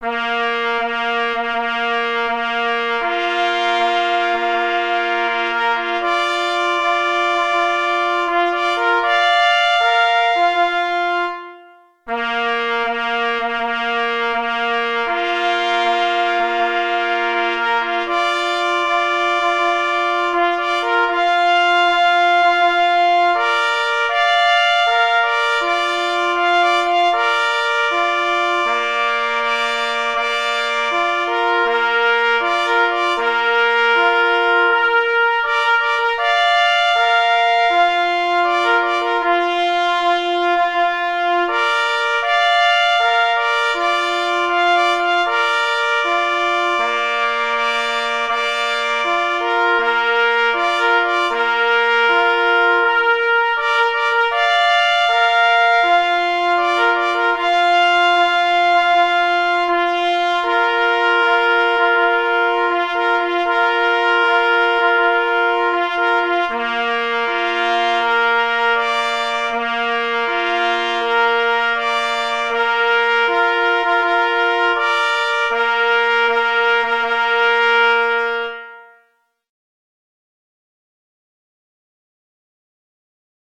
fanfara